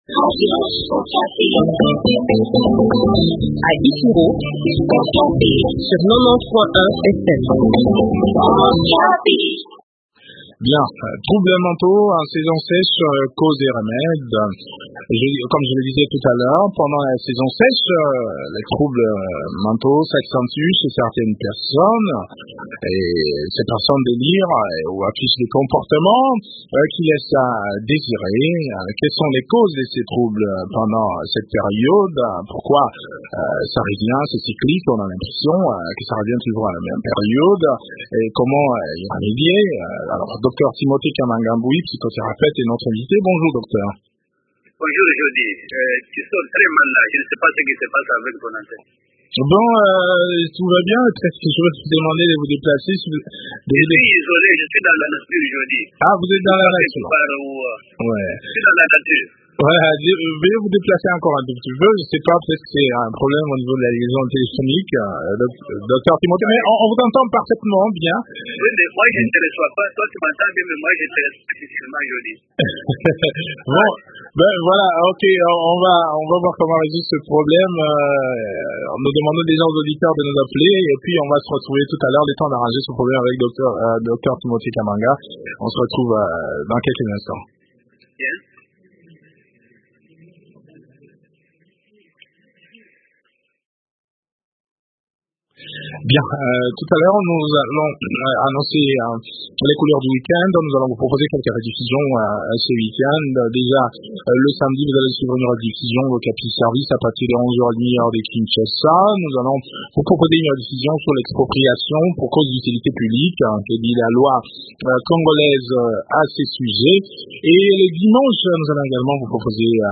psychothérapeute, répond aux questions des auditeurs dans cet échange